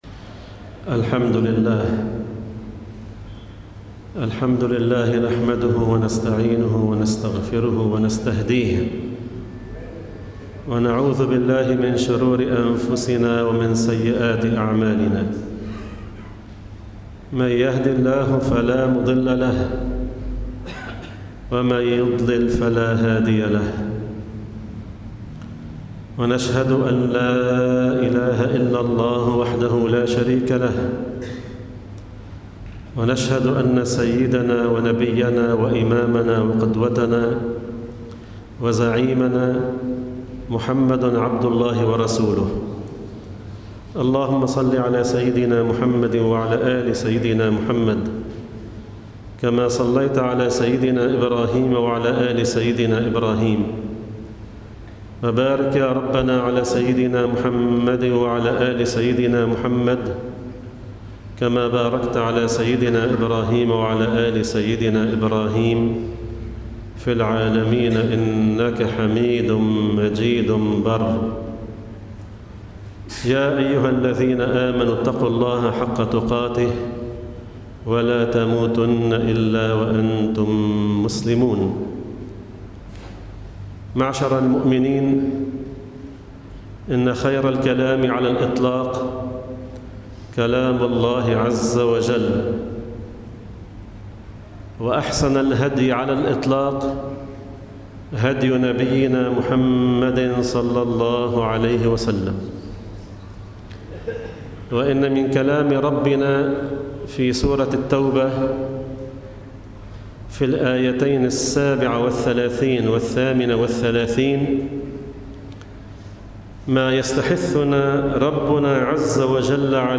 07 يوليو 2017 0 Audio نحن للدين فداء! – خطبة جمعة
في مسجد التقوى في طرابلس